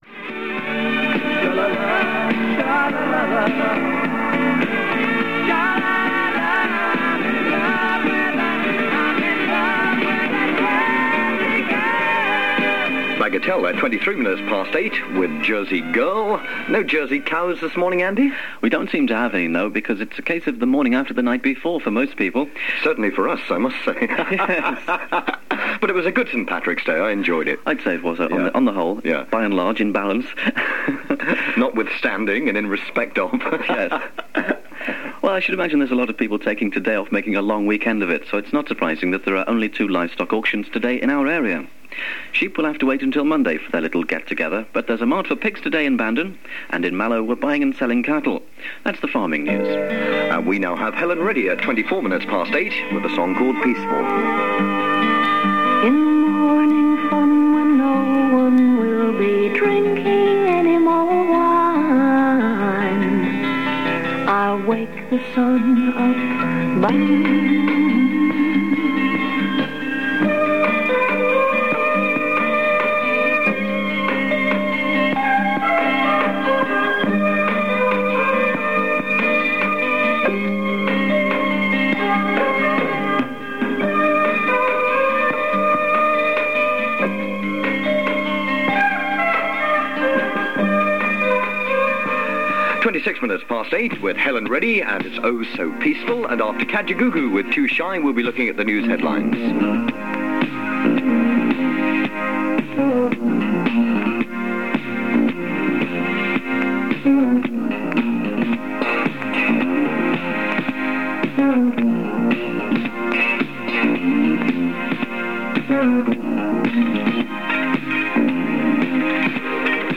By 1983, ERI had become Cork’s leading pirate station following the transfer of studios from the village of Ballycotton to the edge of Cork city and the installation of a powerful new AM transmitter giving coverage of Munster. These airchecked recordings of the Daybreak breakfast show were made around St. Patrick’s Day 1983 and give a sense of the station during this expansion phase.
Adverts feature businesses around Cork city and county and there is a promo for a syndicated show, Soundtrack of the Sixties.